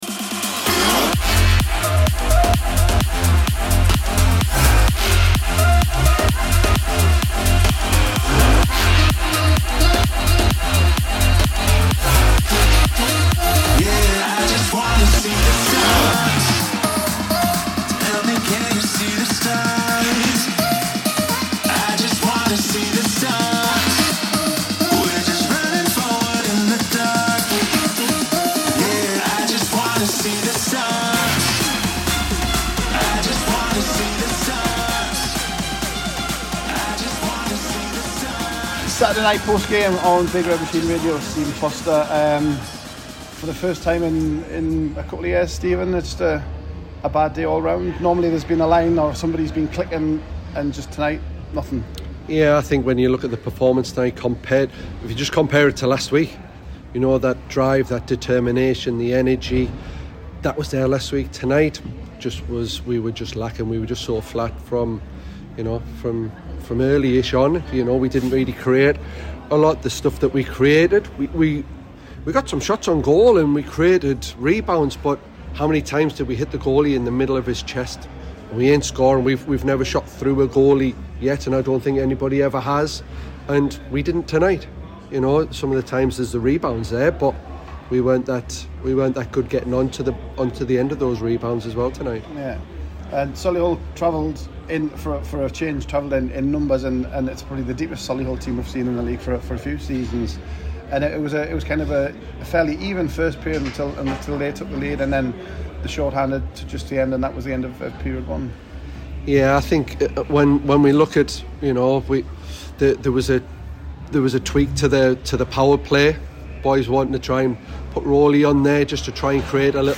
Brutally honest post-game